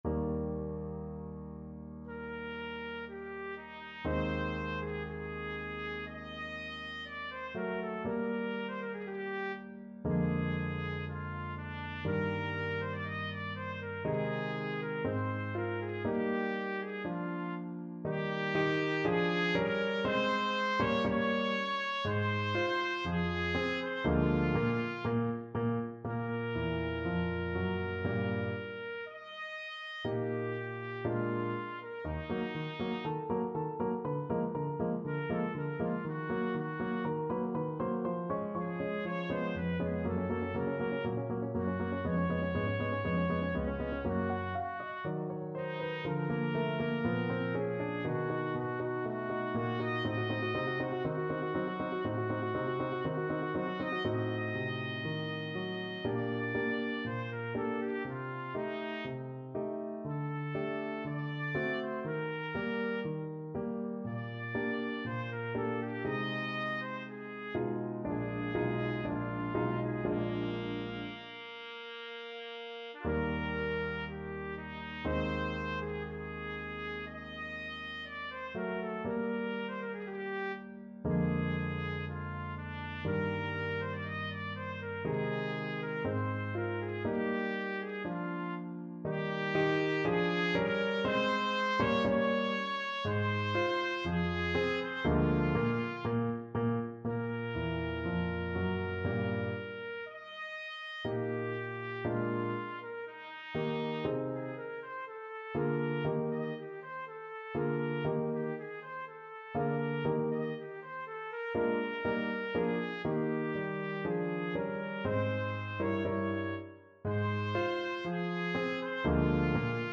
Classical Mozart, Wolfgang Amadeus Dalla Sua Pace from Don Giovanni Trumpet version
Trumpet
Eb major (Sounding Pitch) F major (Trumpet in Bb) (View more Eb major Music for Trumpet )
Andantino sostenuto = c. 60 (View more music marked Andantino)
2/4 (View more 2/4 Music)
Bb4-Eb6
Classical (View more Classical Trumpet Music)